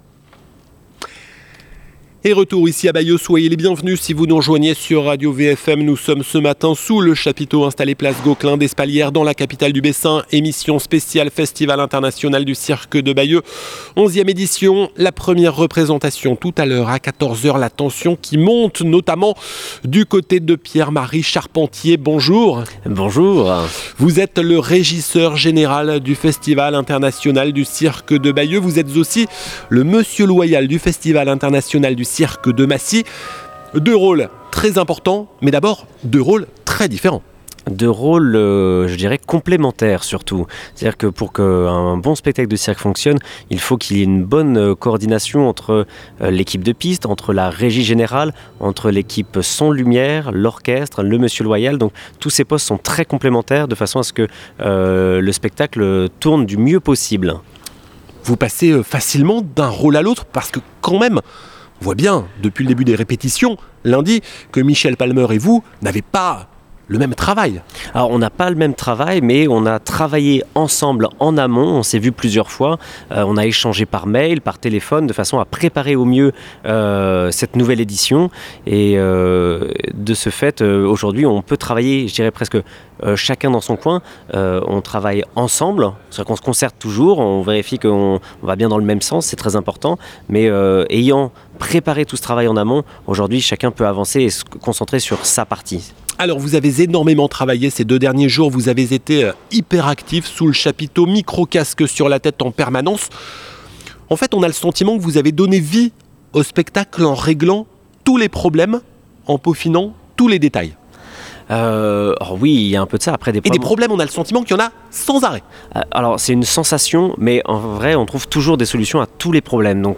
Partie 3 RadioVFM en direct du Festival International du Cirque de Bayeux